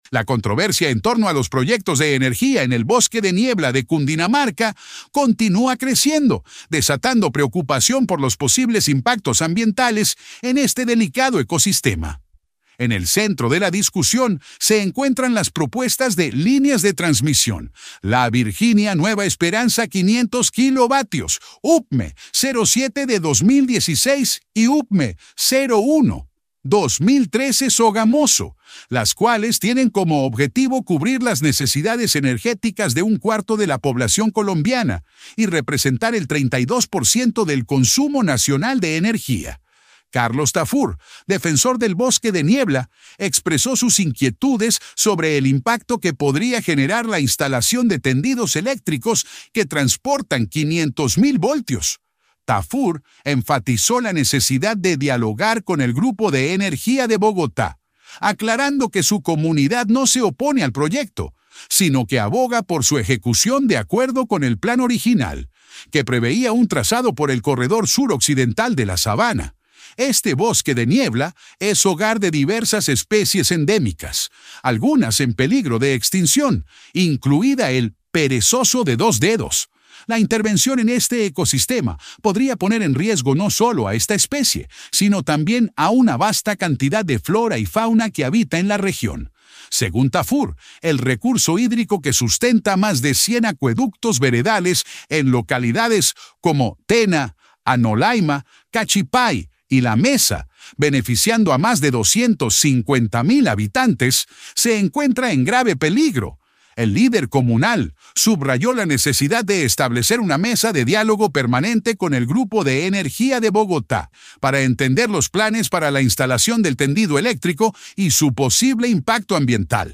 En dialogo con CRISTALINA